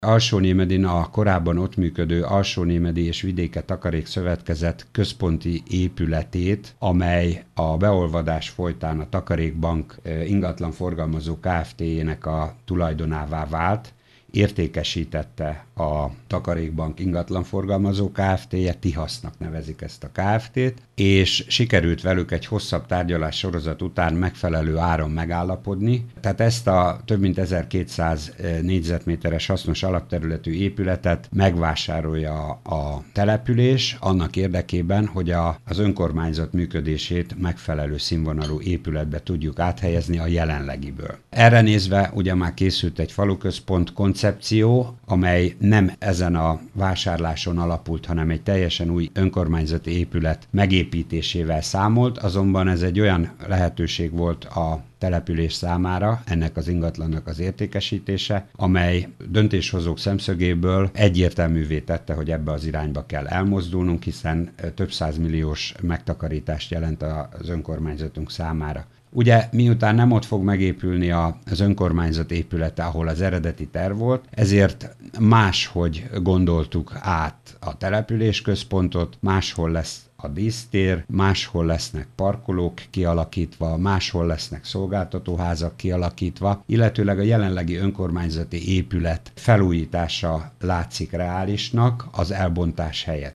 Korábban teljesen új hivatal építésével számoltak, azonban a vásárlás lehetősége jelentős megtakarítást jelent a településnek. Dr. Tüske Zoltán polgármester arról beszélt, újragondolták ezáltal a városközpontot is.